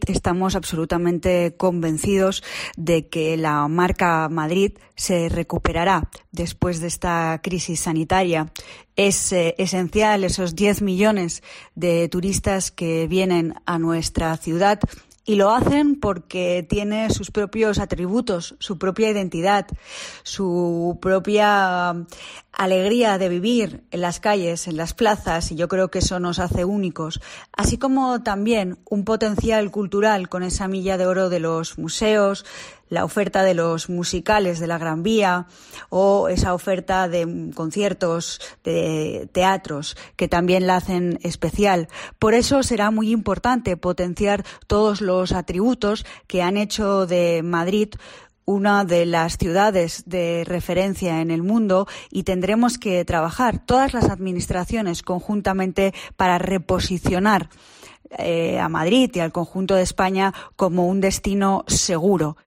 Andrea Levy, Concejal de Cultura, Turismo y Deporta: Las administraciones tienen que trabajar unidas